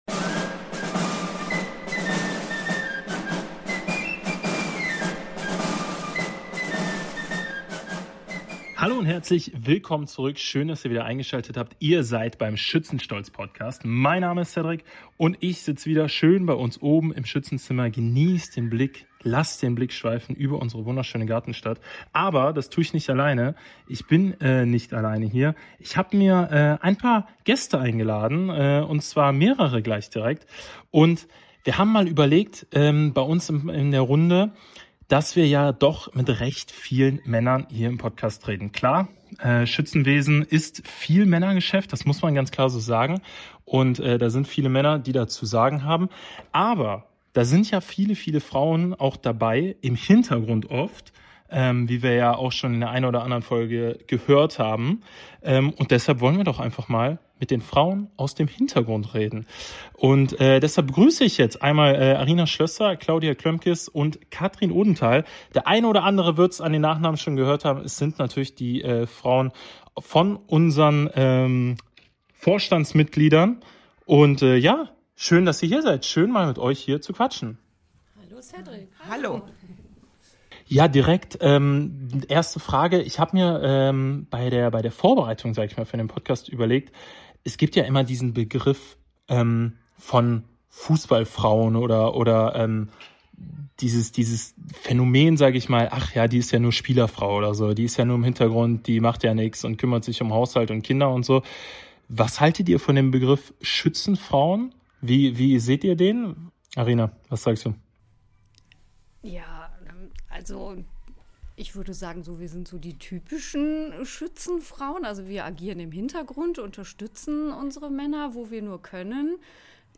In dieser Folge sprechen drei Frauen ganz offen über ihre Rolle im Schützenumfeld: Welche Aufgaben übernehmen sie rund ums Fest?